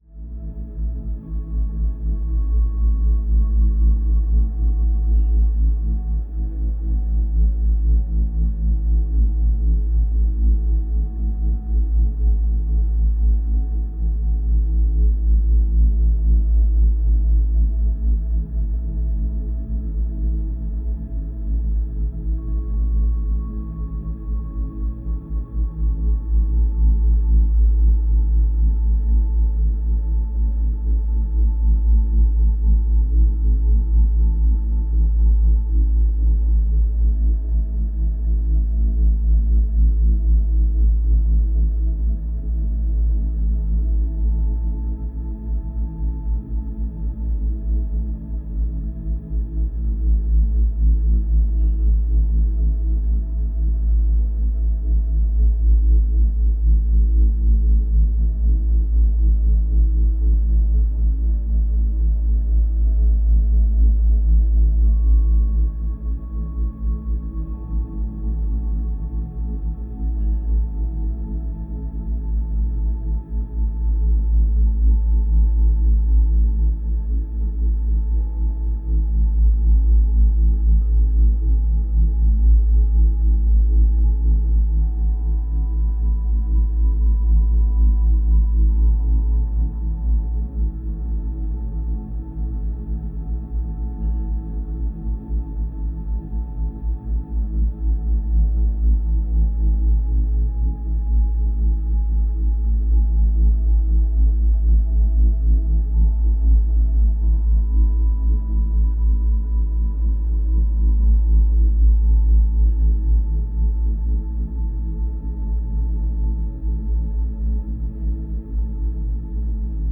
la fréquence naturelle de notre planète (7,83 Hz)